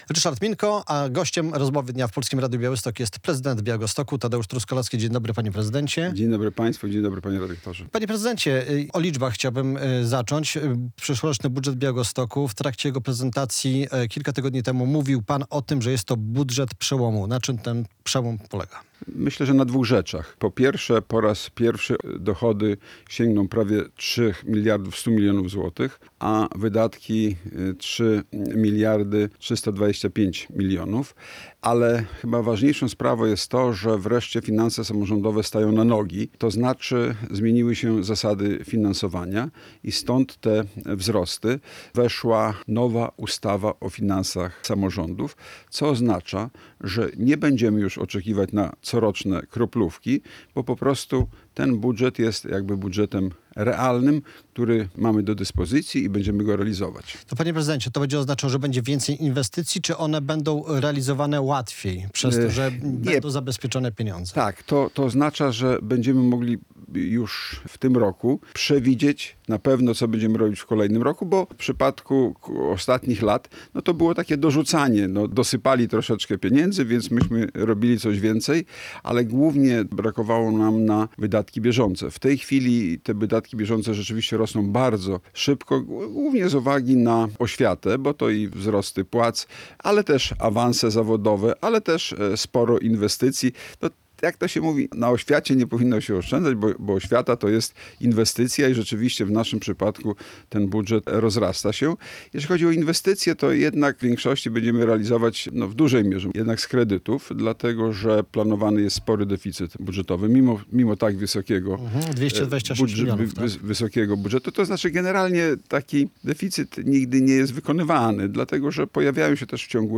- Po raz pierwszy dochody Białegostoku sięgną prawie 3 mld 100 mln złotych, a wydatki 3 mld 325 mln zł. Ale wreszcie finanse samorządowe stają na nogi. Zmieniły się zasady finansowania i stąd te wzrosty - mówił w Polskim Radiu Białystok Prezydent Białegostoku Tadeusz Truskolaski.